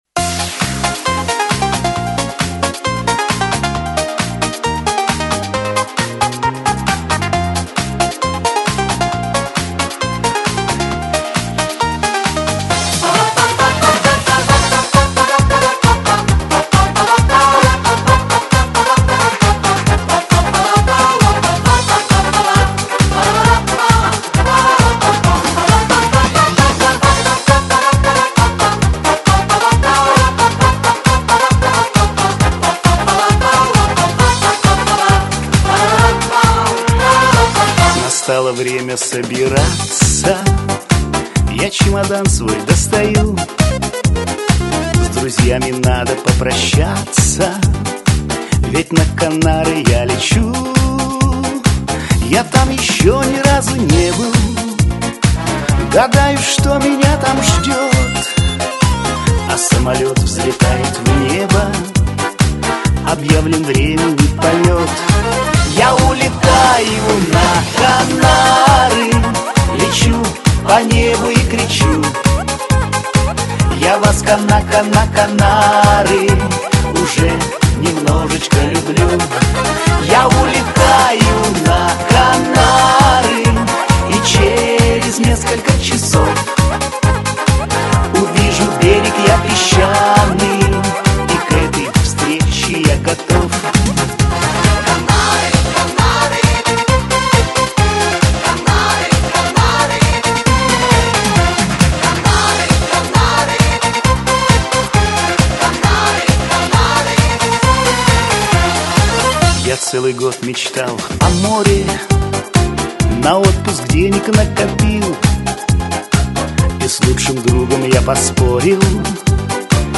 Всі мінусовки жанру Shanson
Плюсовий запис